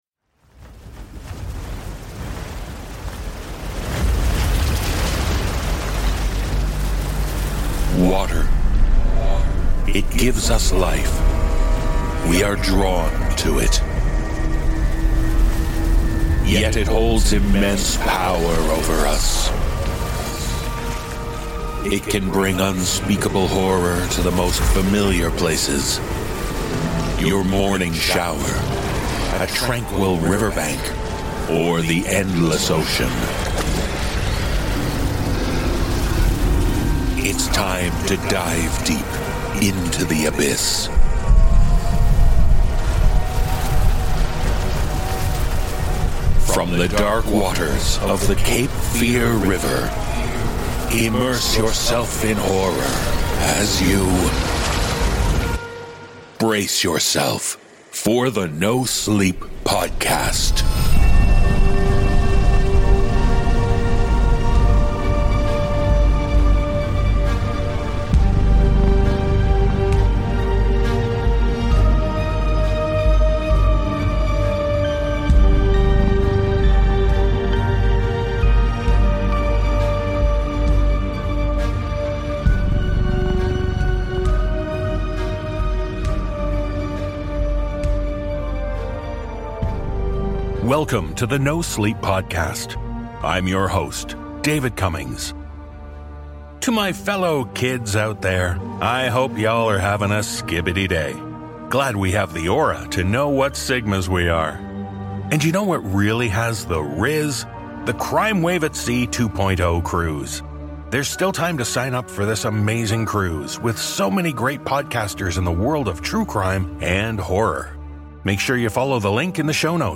The NoSleep Podcast is Human-made for Human Minds.